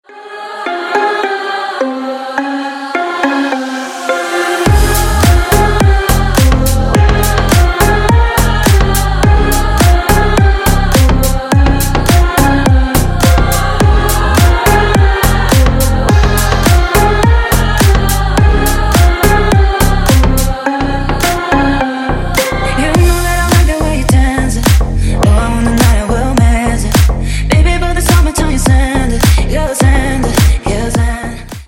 Клубные Рингтоны » # Громкие Рингтоны С Басами
Рингтоны Ремиксы » # Танцевальные Рингтоны